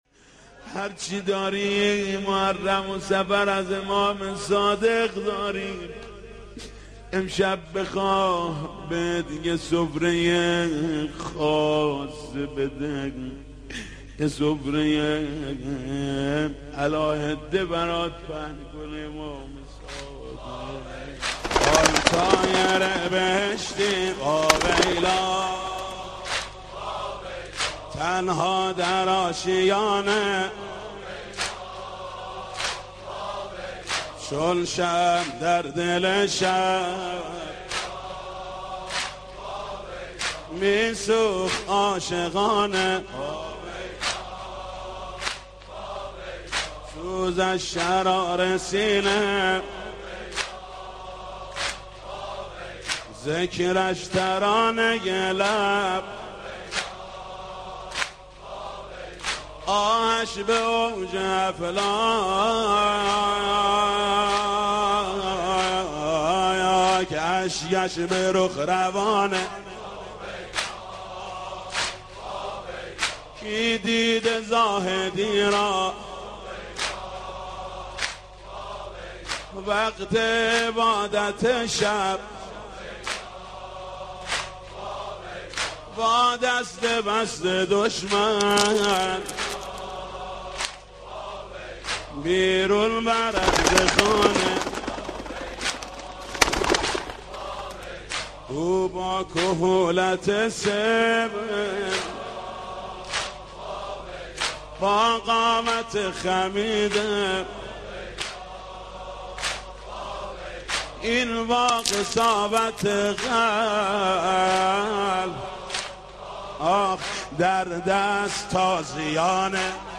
واحد خوانی حاج محمود کریمی در شهادت امام صادق علیه السلام